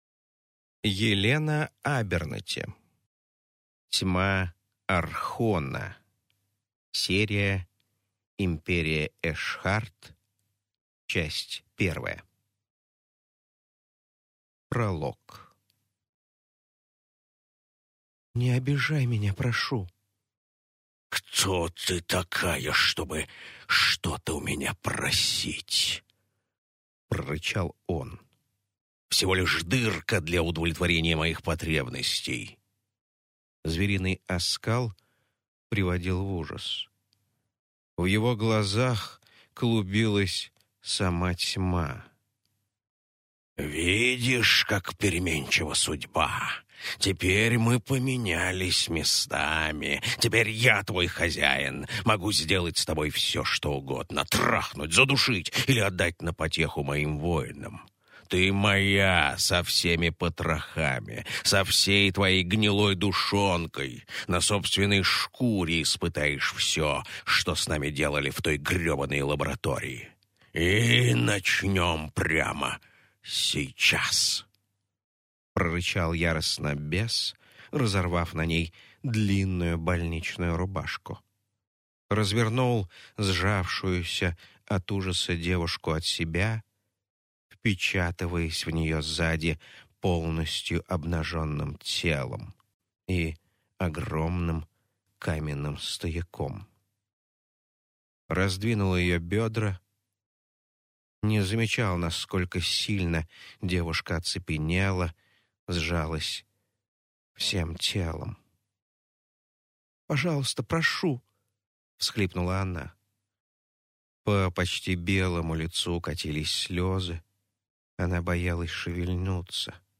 Аудиокнига Тьма архона. Серия «Империя Эшхарт – 1» | Библиотека аудиокниг